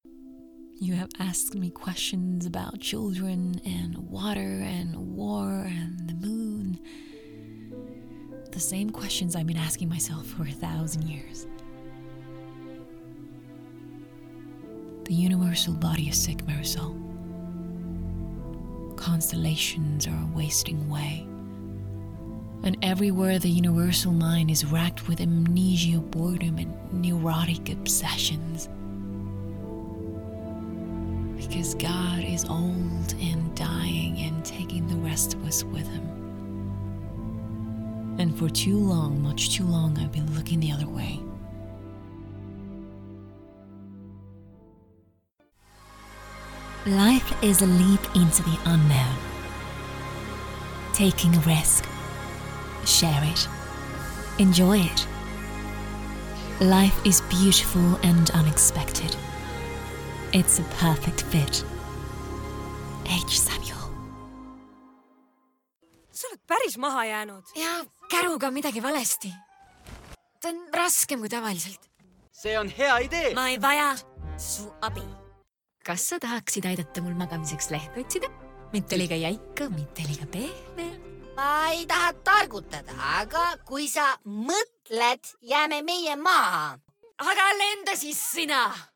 Commerciale, Naturelle, Polyvalente, Chaude
She speaks English and Estonian as her primary languages with an emphasis on RP, Standard American and Estonian delivered in a warm, seductive and smooth tone.